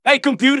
synthetic-wakewords / hey_computer /ovos-tts-plugin-deepponies_Scout_en.wav
ovos-tts-plugin-deepponies_Scout_en.wav